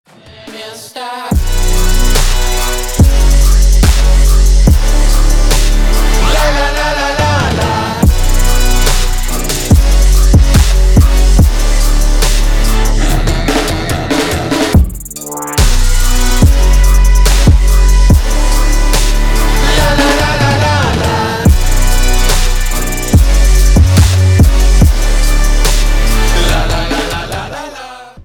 • Качество: 320, Stereo
позитивные
мощные
Bass
Стиль: trap